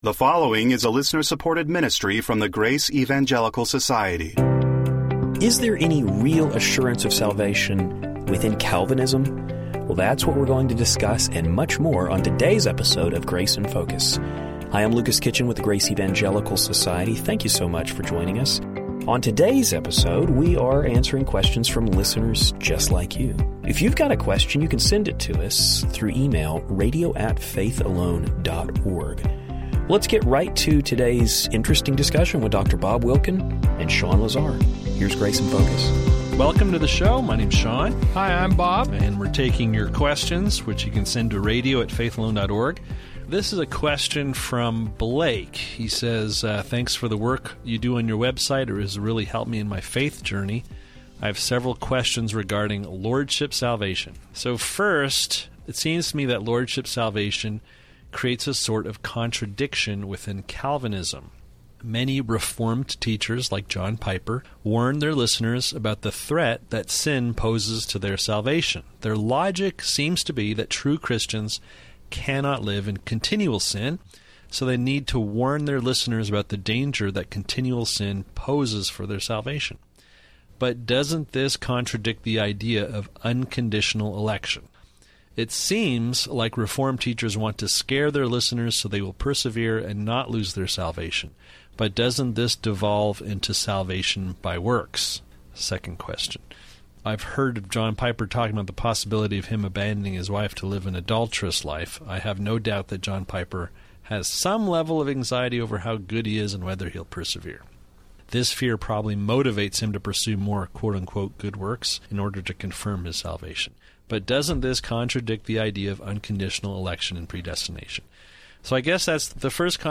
We hope the discussion brings some clarity to this important issue.